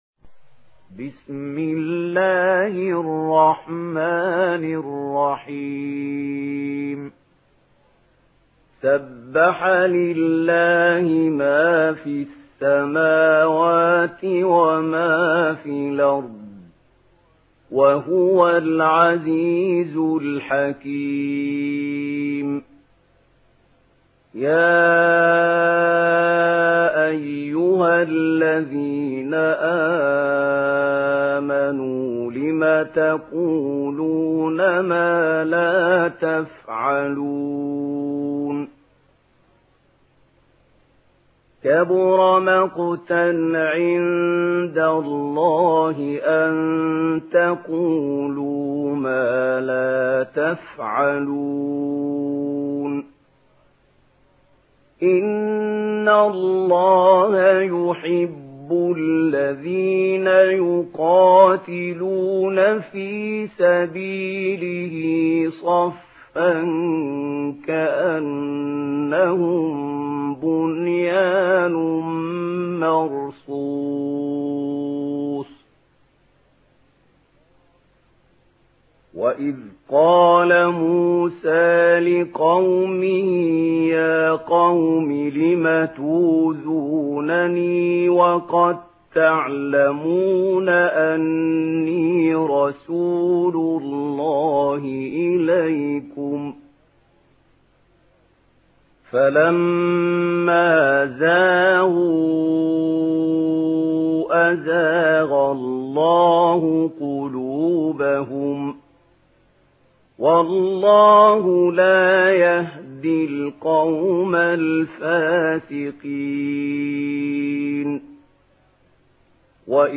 Surah আস-সাফ MP3 by Mahmoud Khalil Al-Hussary in Warsh An Nafi narration.
Murattal